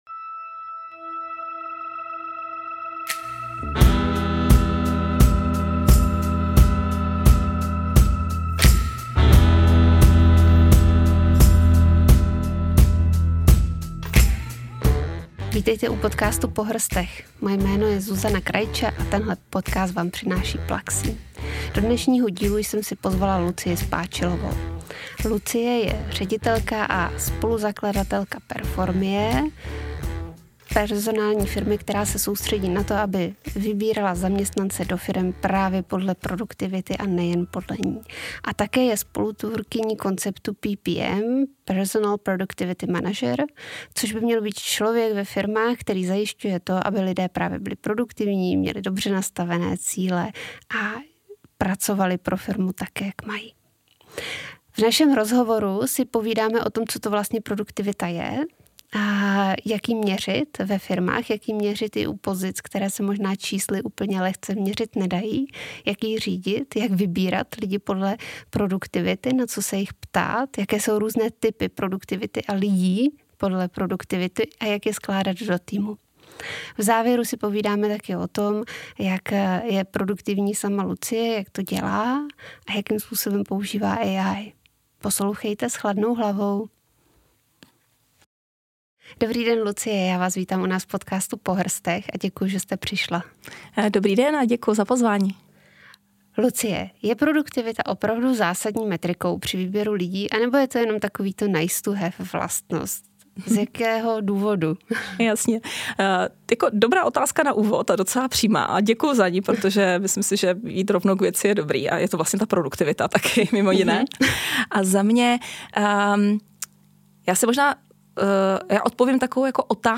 V rozhovoru mluvíme o tom, co je to efektivita a jak ji měřit i u méně uchopitelných pozic, jaké jsou praktické tipy na zvýšení produktivity a jak správně skládat týmy. Na závěr se podělí o své osobní tipy i o to, jak využívá umělou inteligenci.